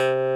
noise7.ogg